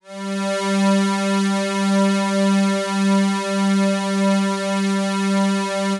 G3_trance_pad_2.wav